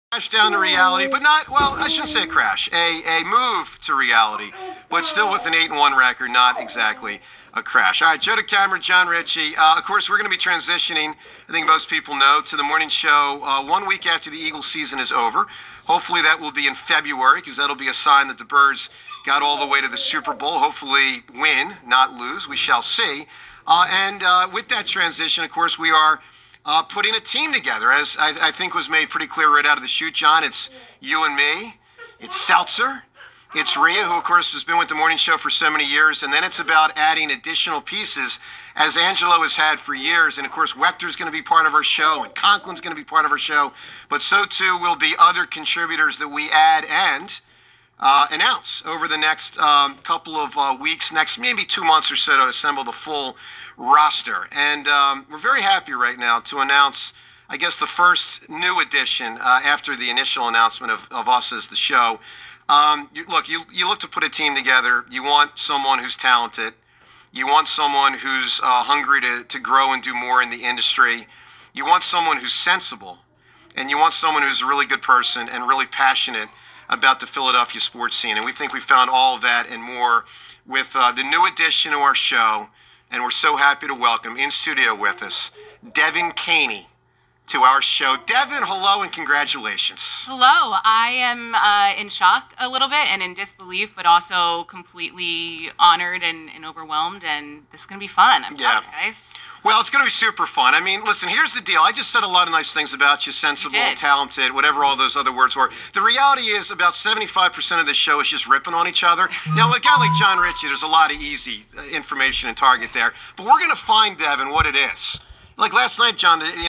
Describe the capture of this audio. Sorry for the background noise, that’s my sick kid.